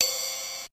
Machine_ride.wav